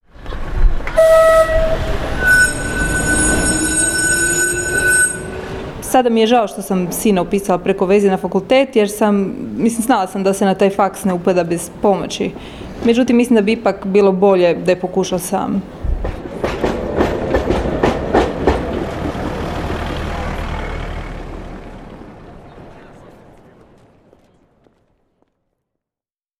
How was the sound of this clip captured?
STATEMENTS BROADCASTED ON RADIO "SLJEME":